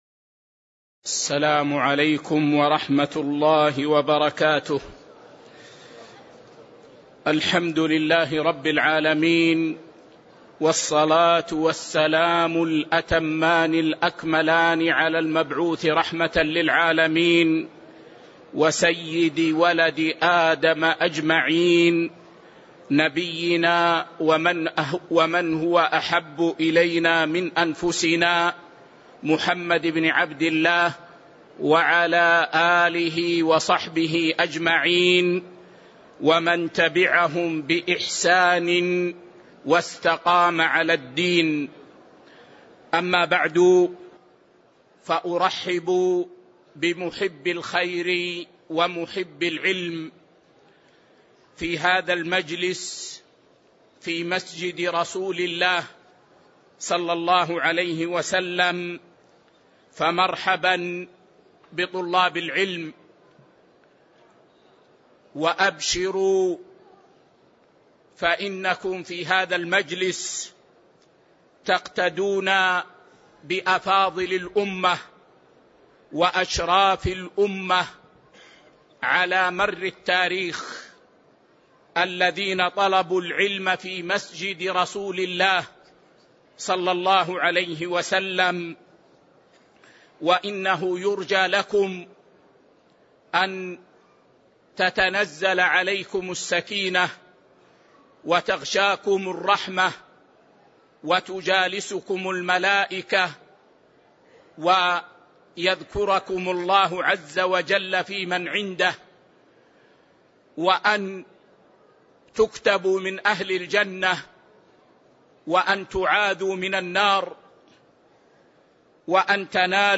تاريخ النشر ١٠ رجب ١٤٤٤ هـ المكان: المسجد النبوي الشيخ